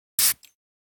perfume1.ogg